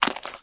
STEPS Swamp, Walk 01.wav